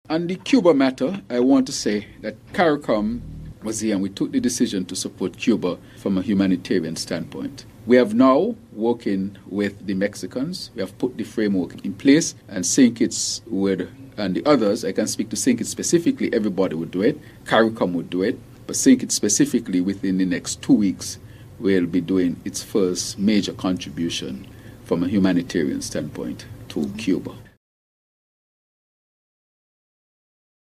That’s Prime Minister and Cuban alumnus, the Hon. Dr. Terrance Drew.